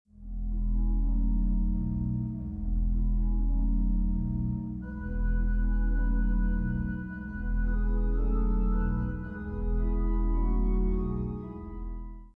recorded  on the Christchurch Town Hall